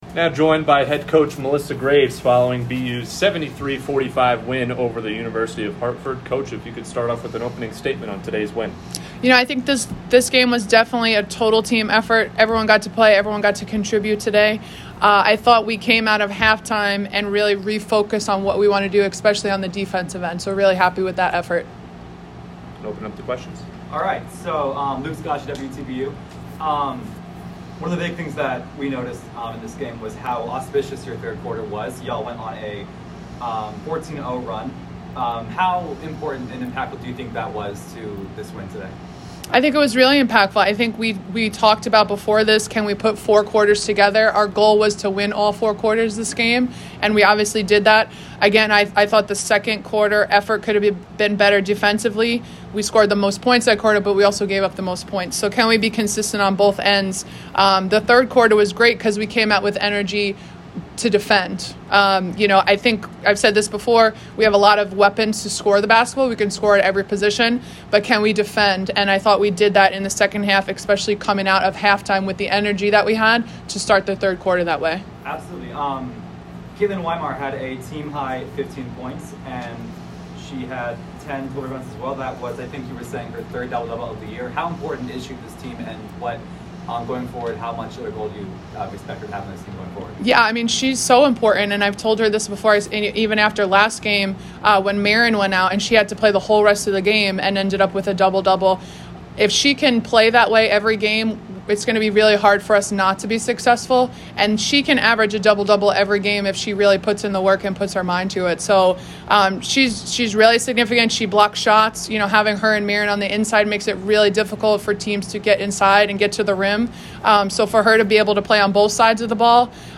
WBB_Hartford_Postgame.mp3